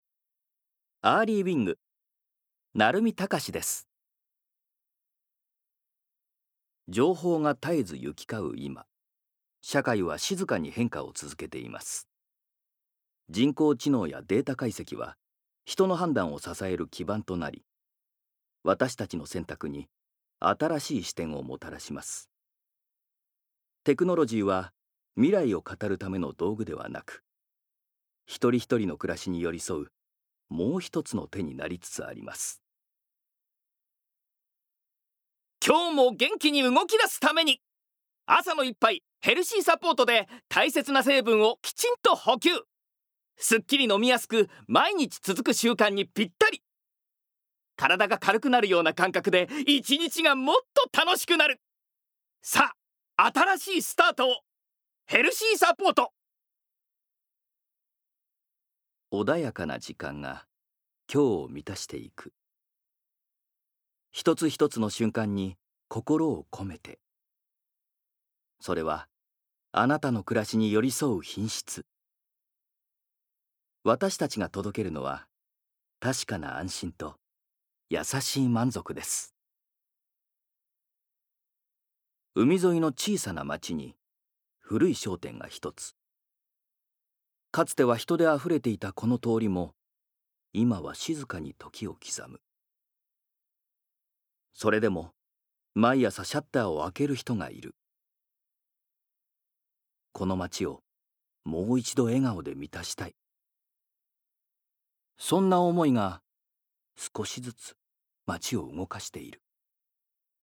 Voice Sample
ナレーションALL